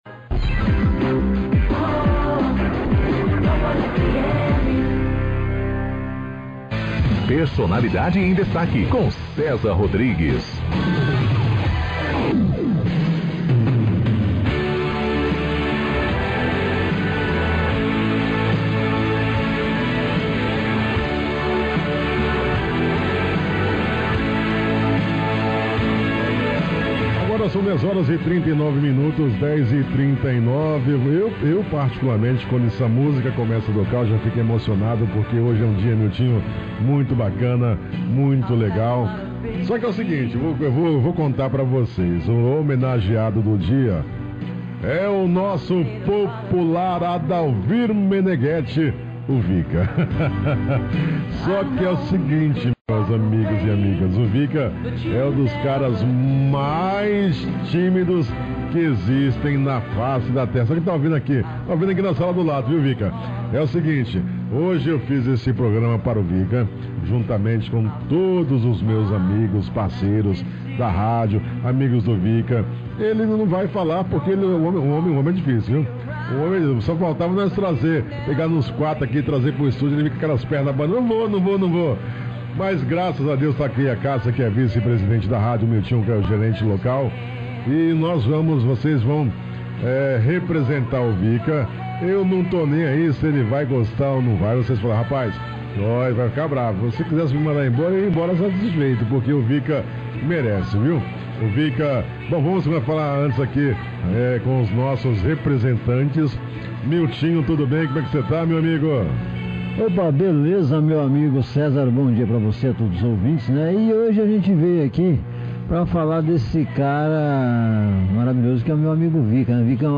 O estúdio recebeu participações especiais de colegas de trabalho